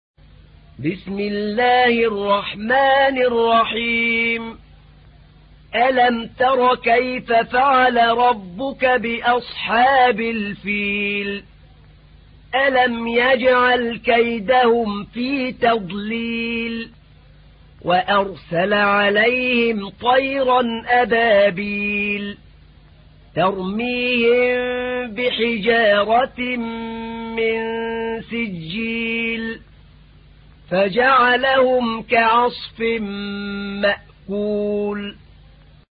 تحميل : 105. سورة الفيل / القارئ أحمد نعينع / القرآن الكريم / موقع يا حسين